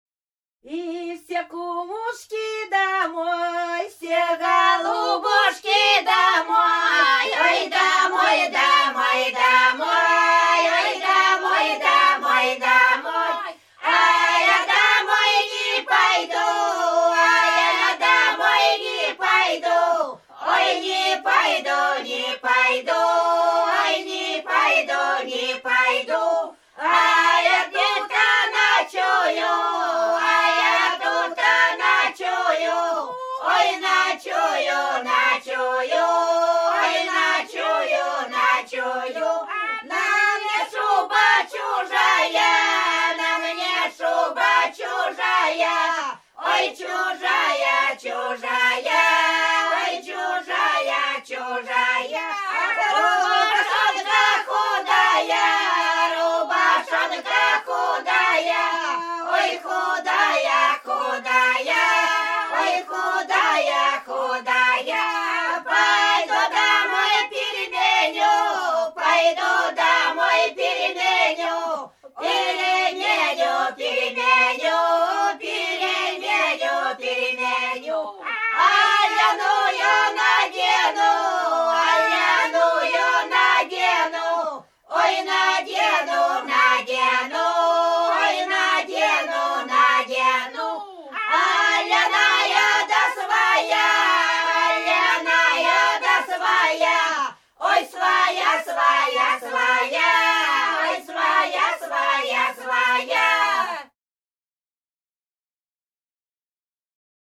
Рязань Кутуково «И все кумушки домой», плясовая.